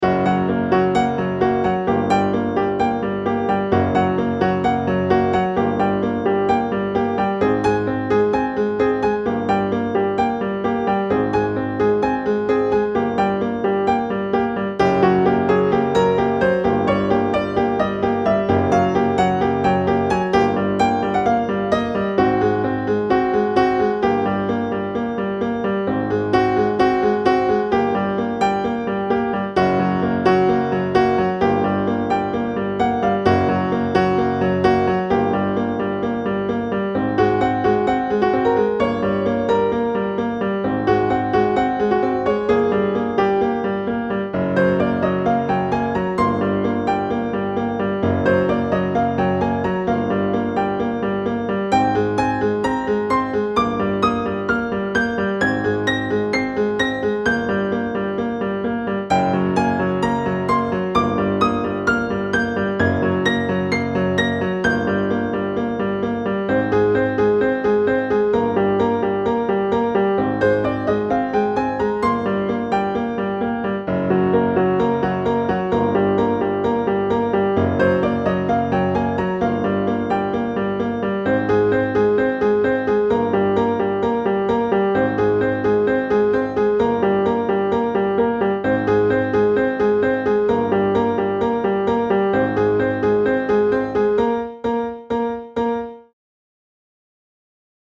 Solo de piano minimalista (escena de resolución)
piano instrumento melodía música repetitivo solo